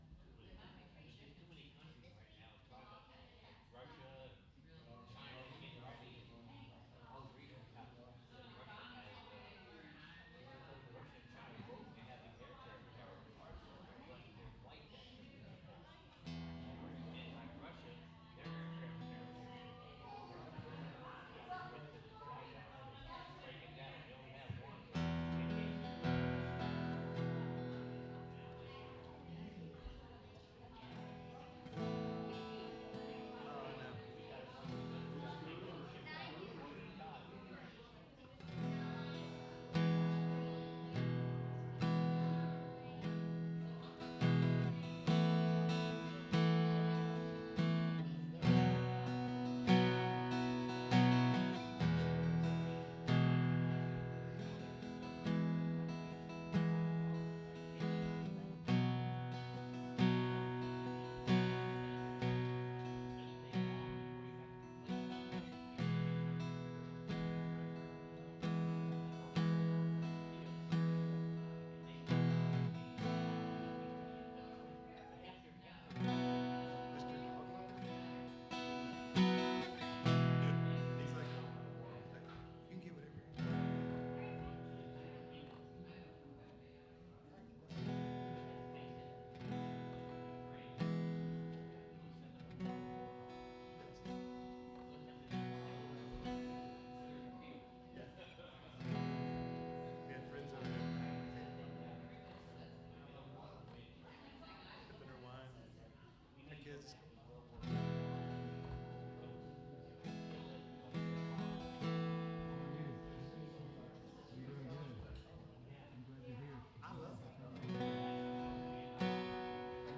SERMON DESCRIPTION Christians can be rigid on different laws or go to the other extreme of “laws don’t matter” and it is all "Christian freedom".